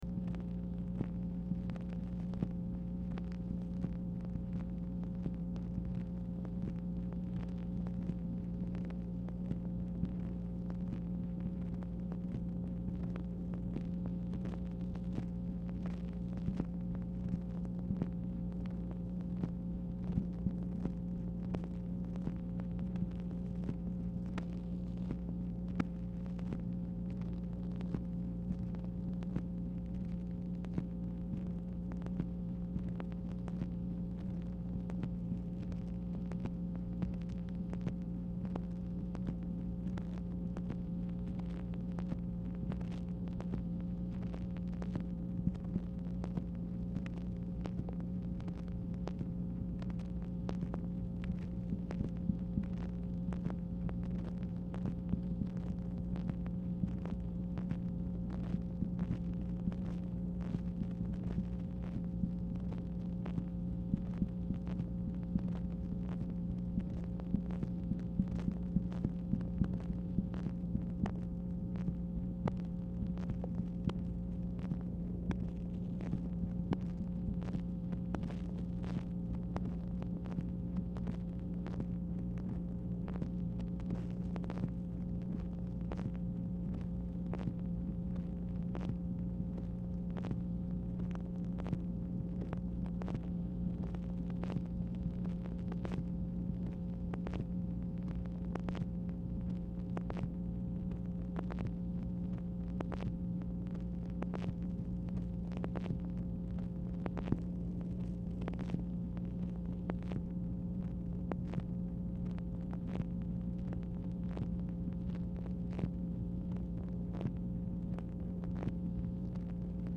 Telephone conversation # 10779, sound recording, MACHINE NOISE, 9/14/1966, time unknown · Discover Production